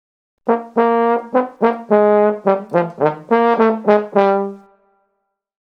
groep6_les1-5-2_blaasinstrumenten7_trombone
groep6_les1-5-2_blaasinstrumenten7_trombone.mp3